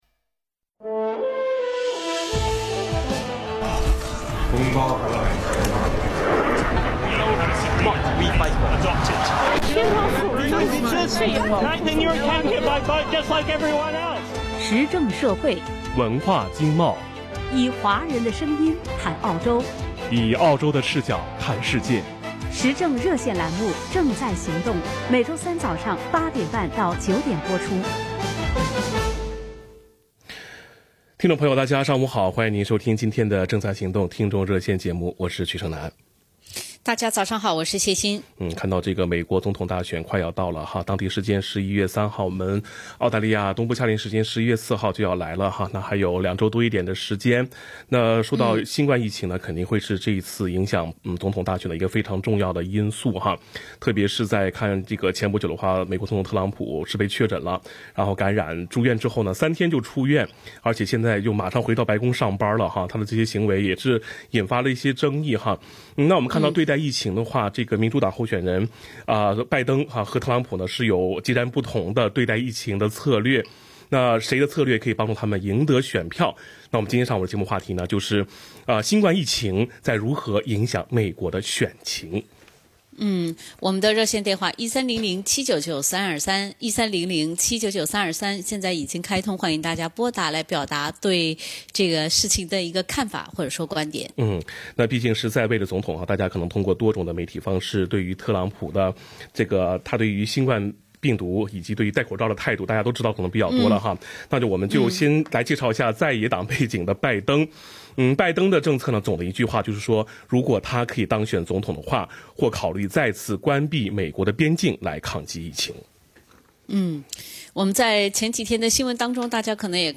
（以上为热线听众发言总结，不代表本台观点） READ MORE 如何订阅SBS中文的newsletter？